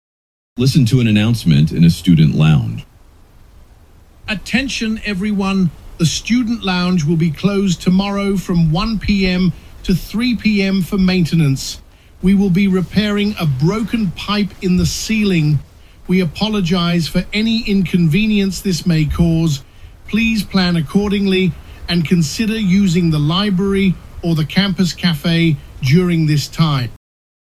ETSはこのタイプの問題について、“a short academic-related announcement (about 40–85 words)” 「学業に関連する短い案内：およそ40〜85語」と説明しており、案内・告知・発表は一人の話者による短いスピーチになります。